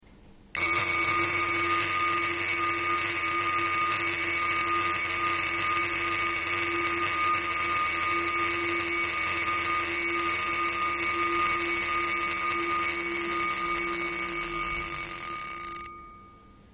The charm of the Memovox, the alarm complication retained its signature “school bell” sound.
81afa4bc-jaeger-lecoultre-polaris-mariner-memovox.mp3